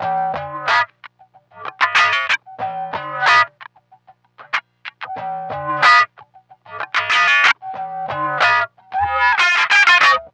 WRNG TWANG-L.wav